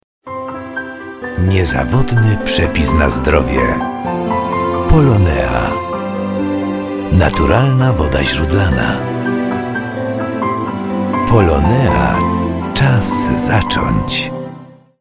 reklama radiowa #3